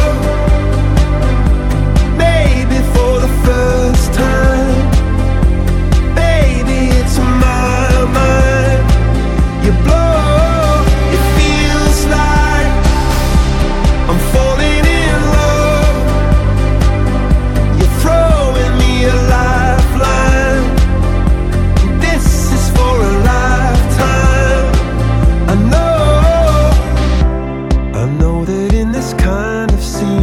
Dance pop, hymnes de stade et ballade amoureuse